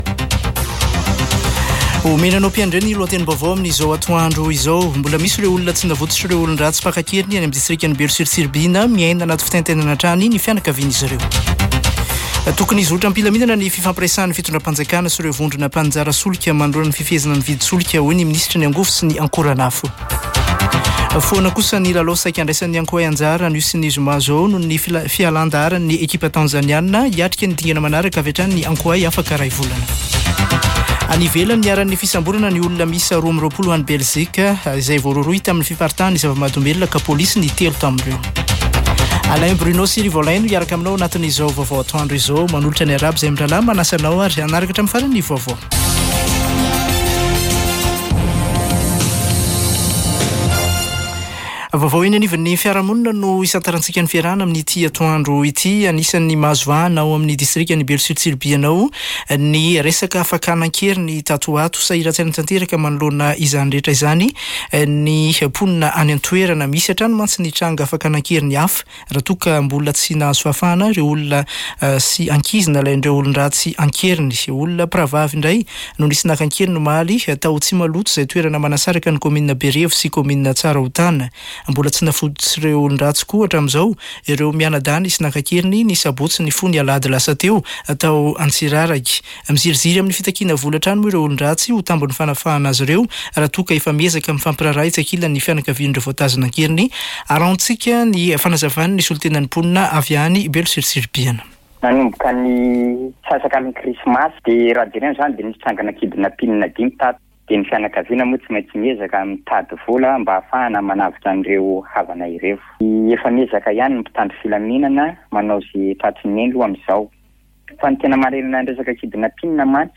[Vaovao antoandro] Alarobia 17 janoary 2024